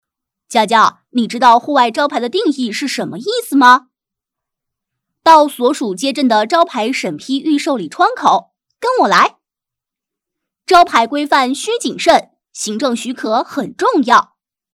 【男童】招牌申请
【男童】招牌申请.mp3